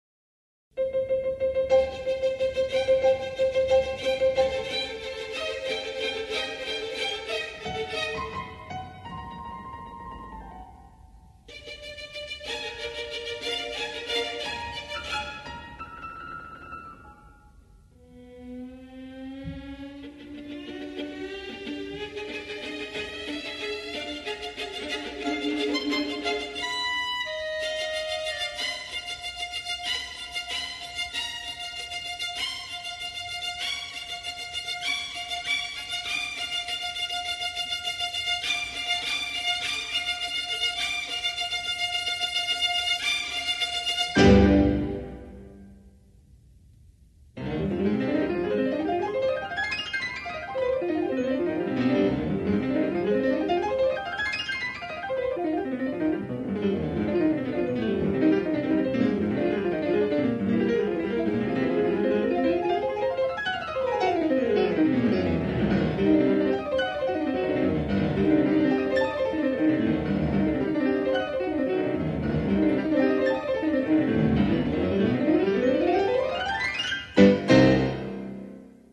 Їхні образи та голоси змалює для нас музика.